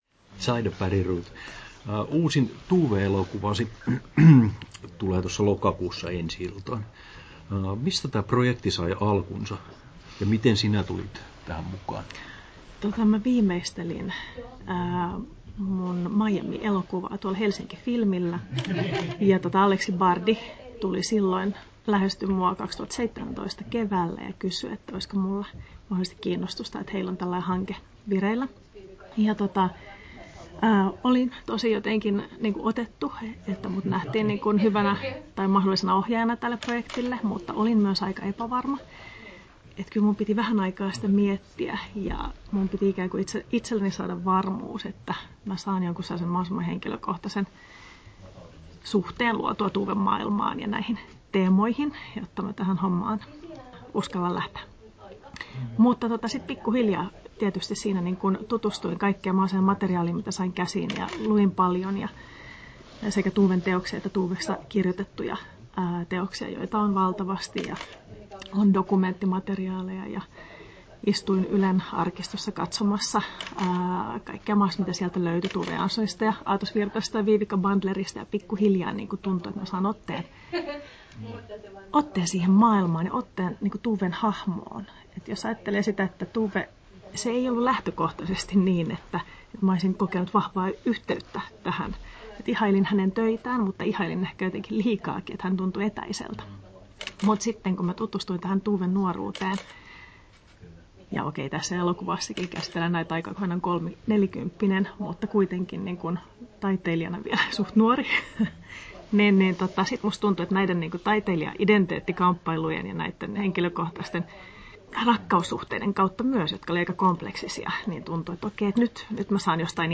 Haastattelussa Zaida Bergroth Kesto: 7'37" Tallennettu: 08.09.2020, Turku Toimittaja